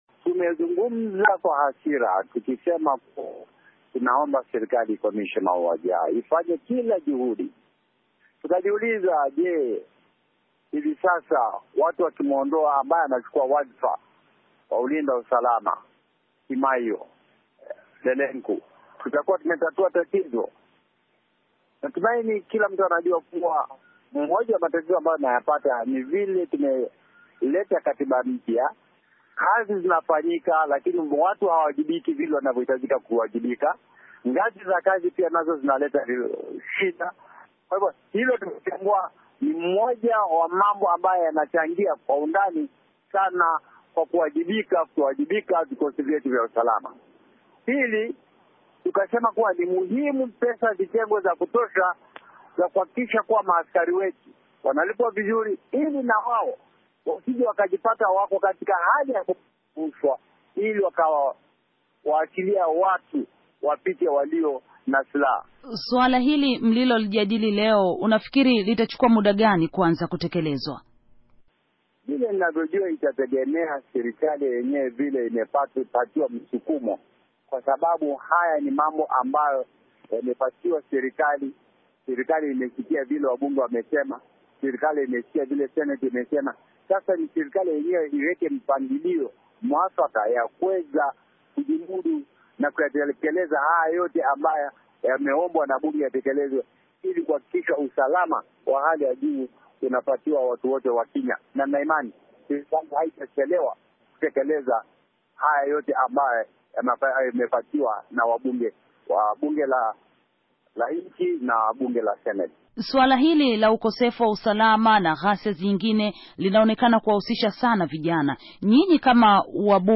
Mahojiano na Mbunge Thomas Mwadegu wa Kaunti ya Taita-Taveta